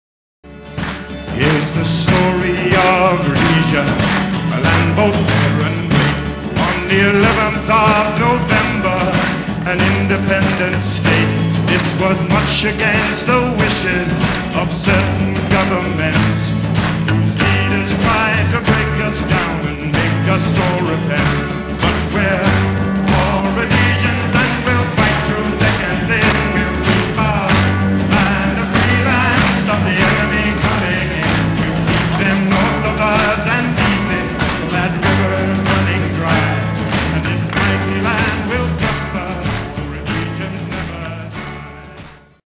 MONO Soundtrack